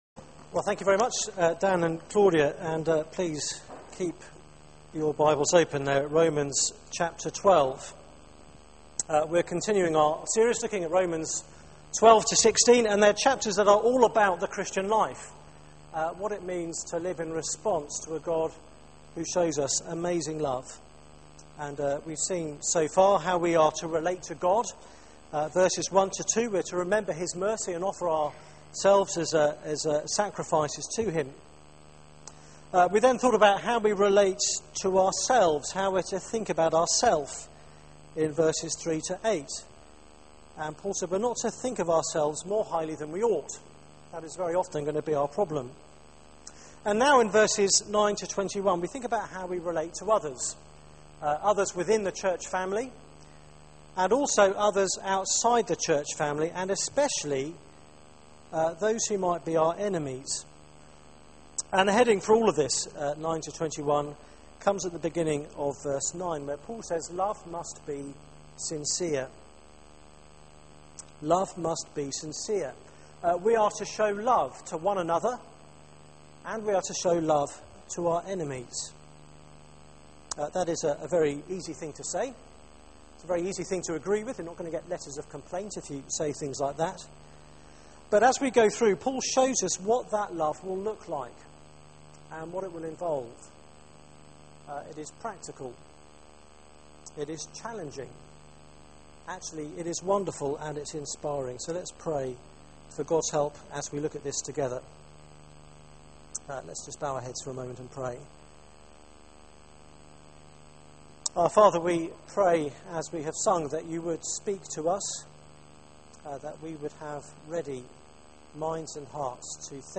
Media for 6:30pm Service on Sun 16th Sep 2012
Theme: Relating to others Sermon (poor sound quality)